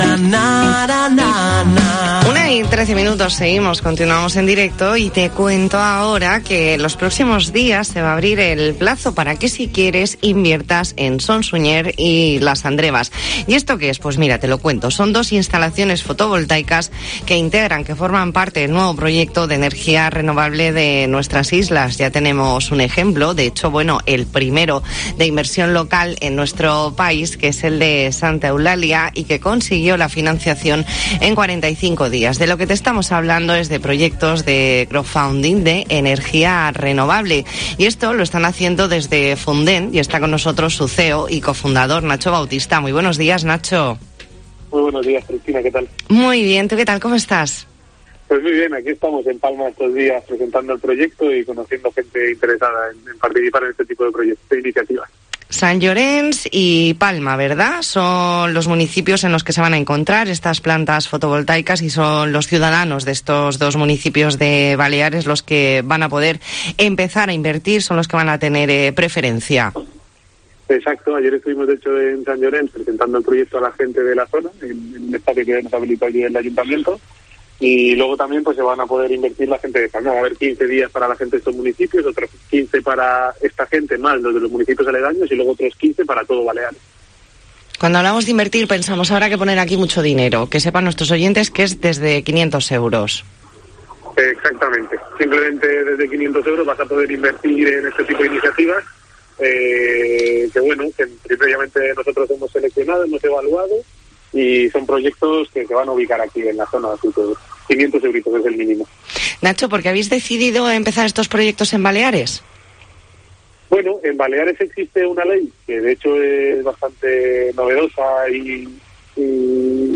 Entrevista en La Mañana en COPE Más Mallorca, miércoles 9 de febrero de 2022.